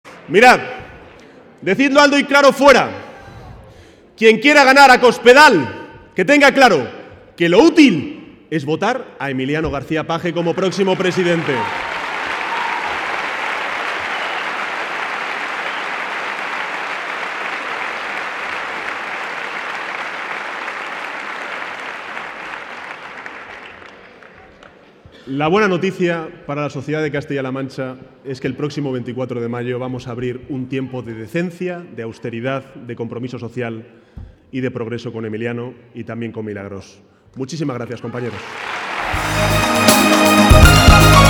García-Page se pronunciaba de esta manera esta tarde, en Toledo, en un acto político que ha congregado a más de 1.500 personas en el Palacio de Congresos de la capital regional y en el que ha compartido escenario con el secretario general del PSOE, Pedro Sánchez, y la candidata socialista a suceder al propio García-Page en la alcaldía de Toledo, Mlagros Tolón.